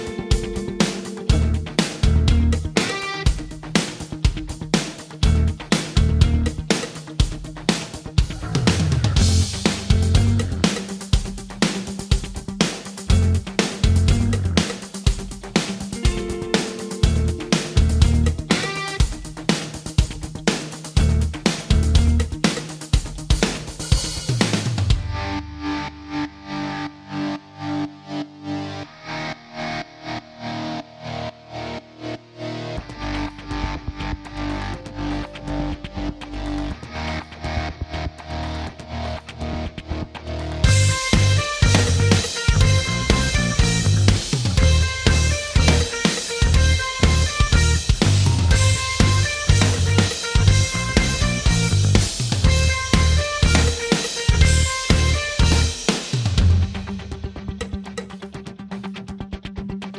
Minus Main Guitars For Guitarists 3:24 Buy £1.50